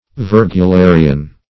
Virgularian \Vir`gu*la"ri*an\, n. [From. L. virgula a small